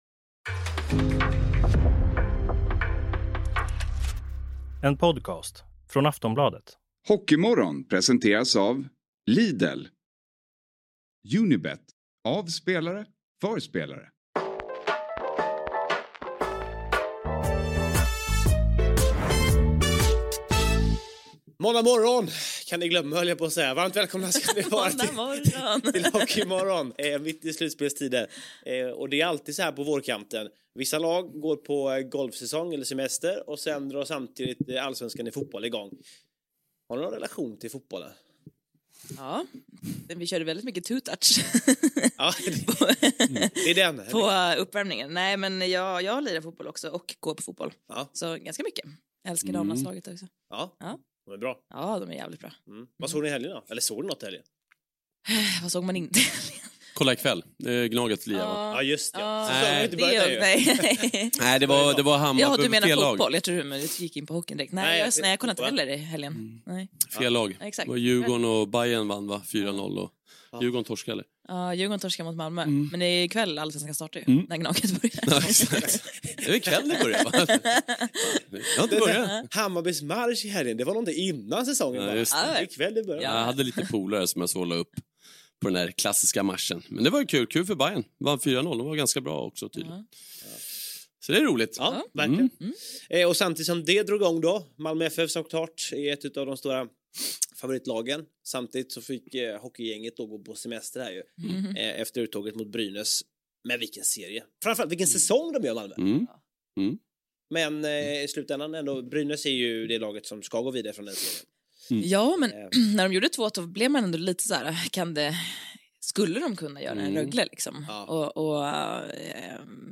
I studion:
På länk: